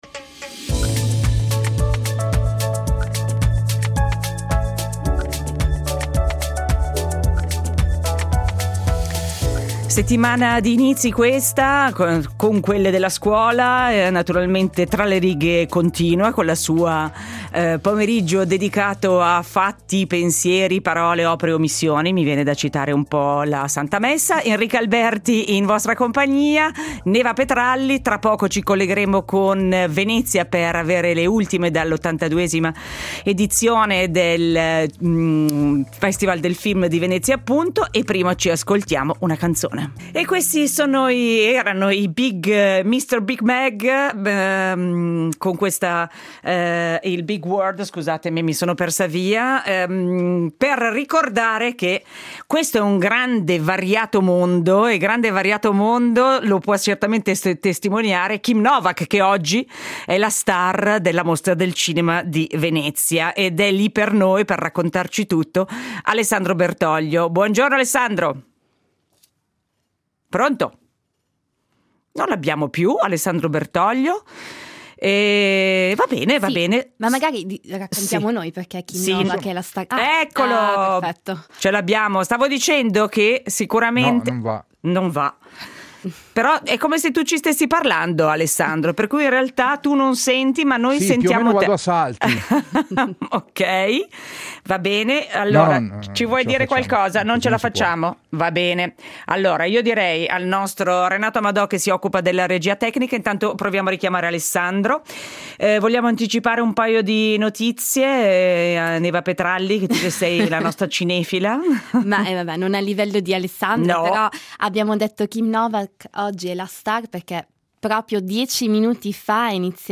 In diretta da Venezia